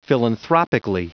Prononciation du mot philanthropically en anglais (fichier audio)
Prononciation du mot : philanthropically